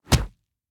punch12.ogg